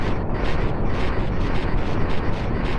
lg_hum.ogg